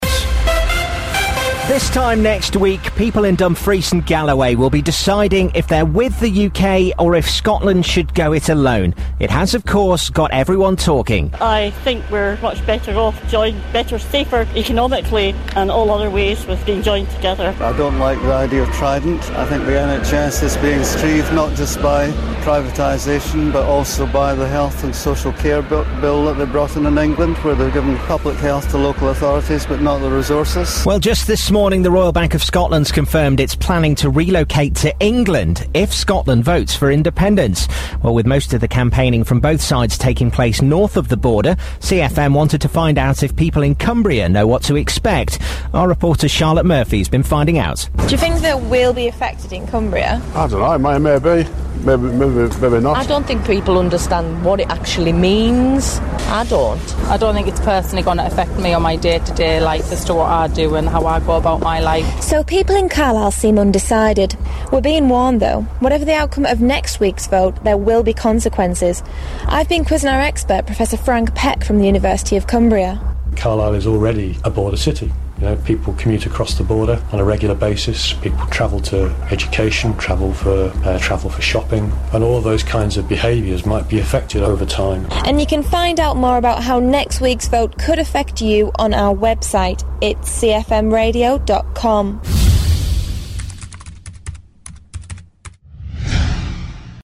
CFM's special report on how the vote can affect people living on both sides of the border.